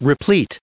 added pronounciation and merriam webster audio
1774_replete.ogg